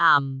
speech
cantonese
syllable
pronunciation
aam4.wav